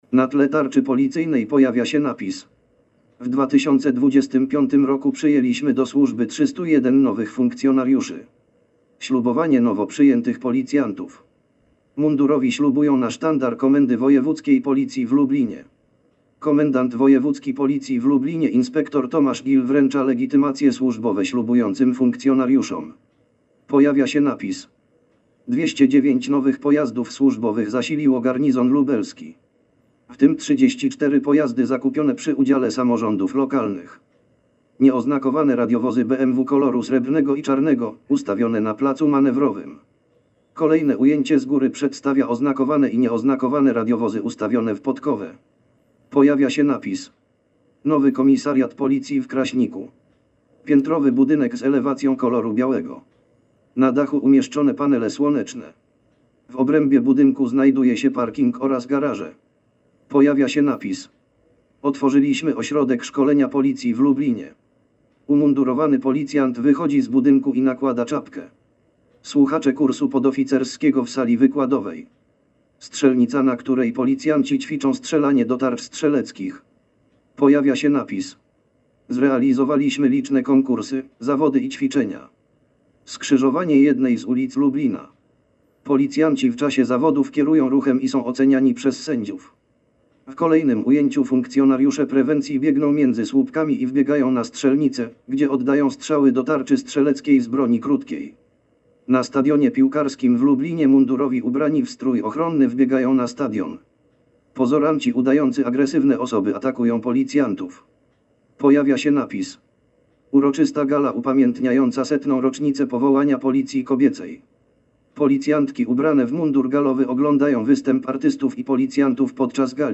Nagranie audio Audiodeskrypcja filmu Lubelska Policja